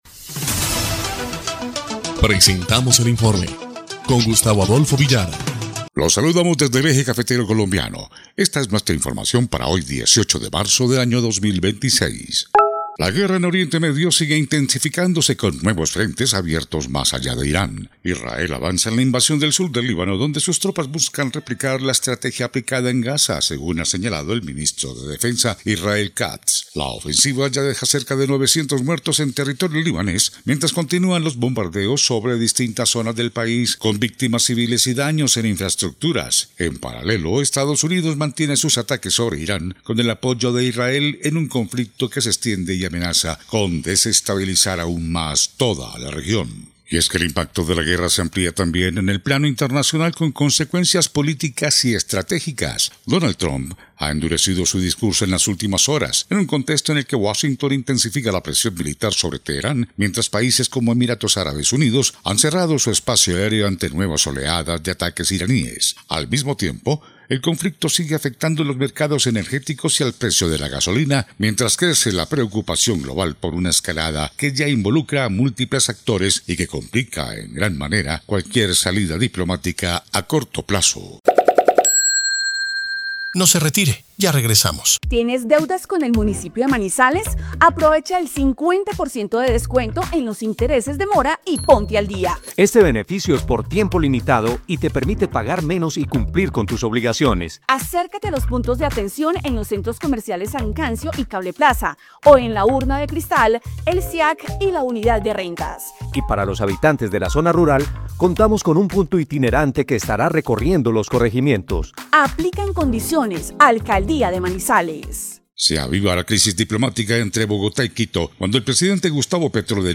EL INFORME 2° Clip de Noticias del 18 de marzo de 2026